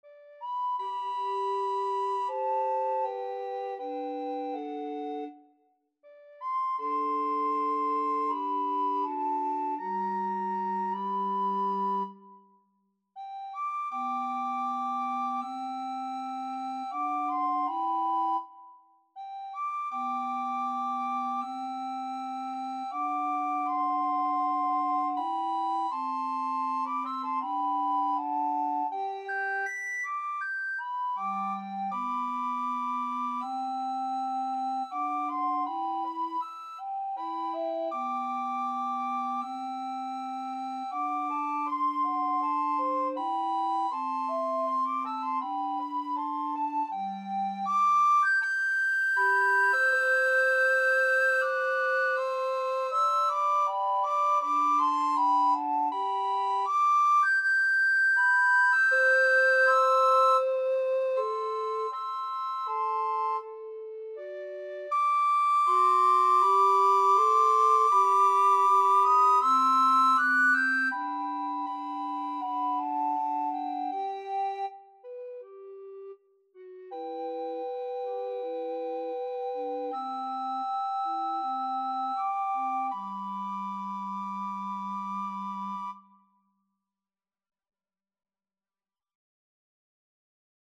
Free Sheet music for Recorder Trio
Soprano RecorderAlto RecorderBass Recorder
4/4 (View more 4/4 Music)
G major (Sounding Pitch) (View more G major Music for Recorder Trio )
Andante